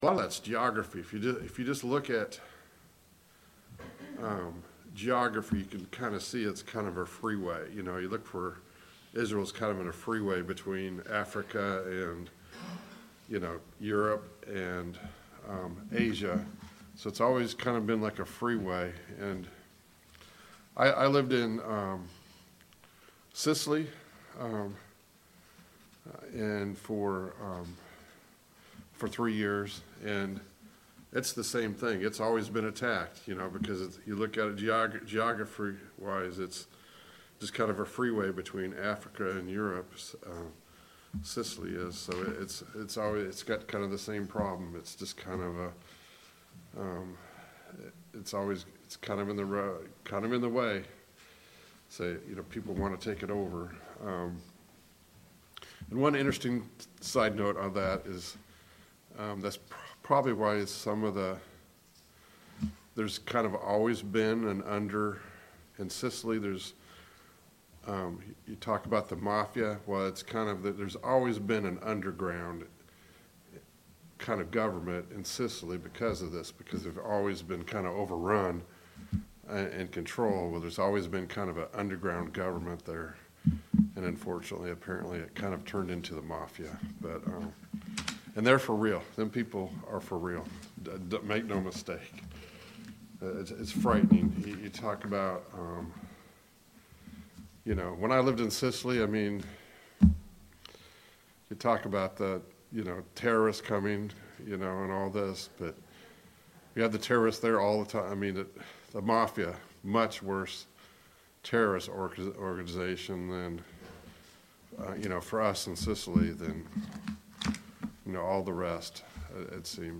Passage: Ezekiel 1 Service Type: Sunday Morning Bible Class « Study of Paul’s Minor Epistles